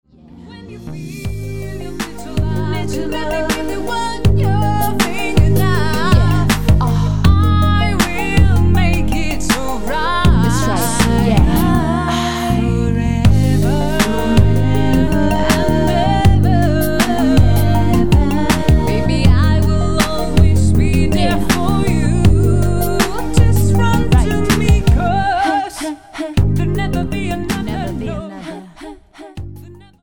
HIPHOP, RAP KARAOKE CDs
NOTE: Vocal Tracks 10 Thru 18